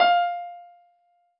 piano-ff-57.wav